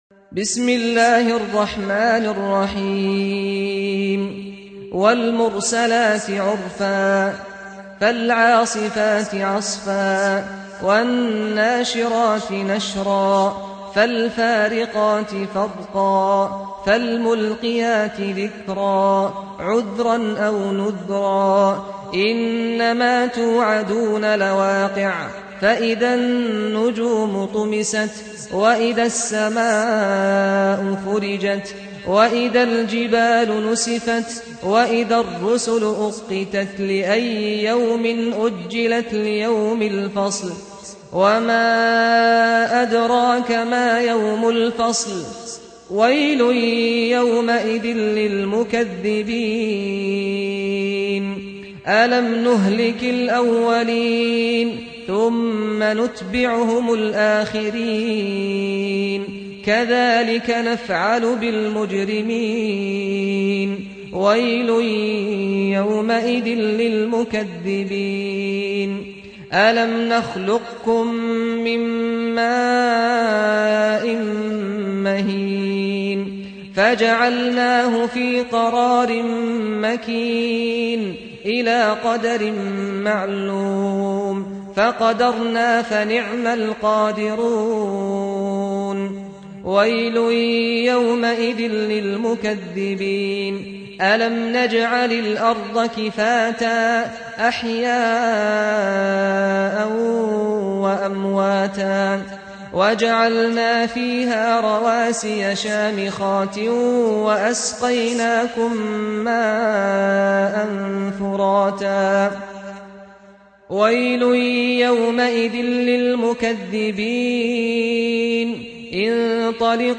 سُورَةُ المُرۡسَلَاتِ بصوت الشيخ سعد الغامدي